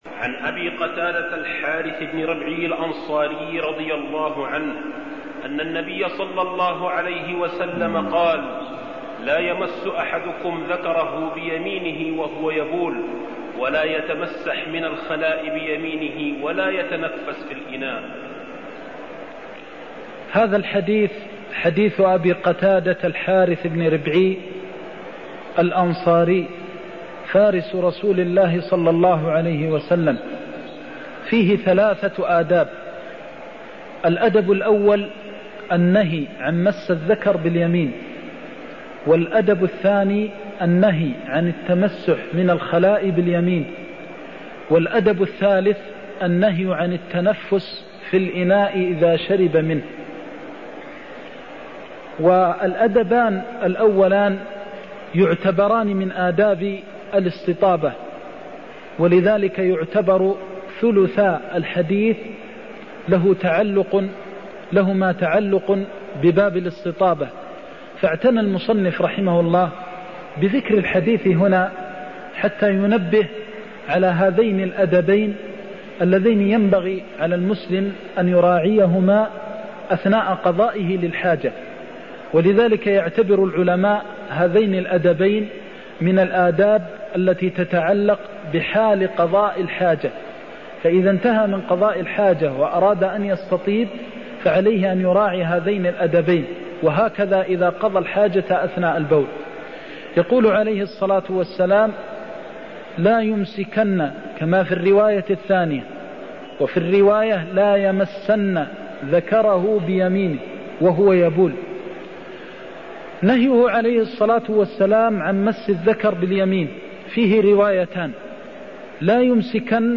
المكان: المسجد النبوي الشيخ: فضيلة الشيخ د. محمد بن محمد المختار فضيلة الشيخ د. محمد بن محمد المختار لا يمس أحدكم ذكره بيمينه وهو يبول (16)